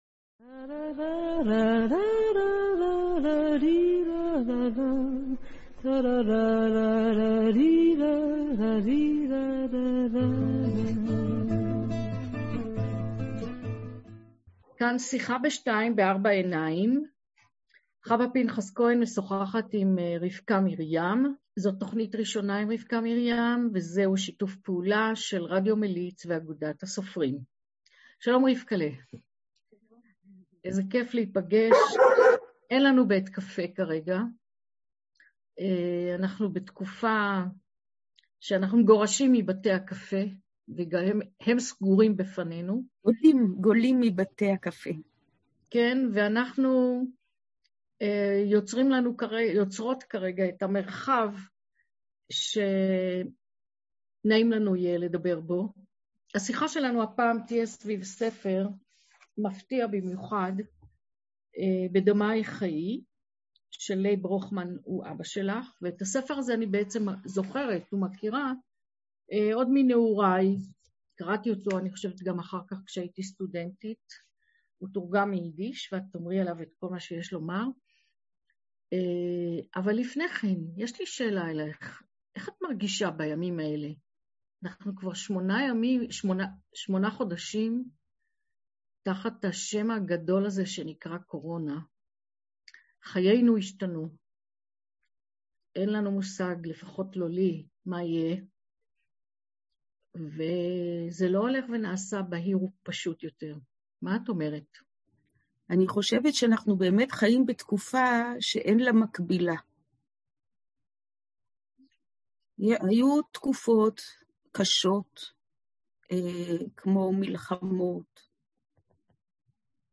שיחה עם רבקה מרים Rivka Miriam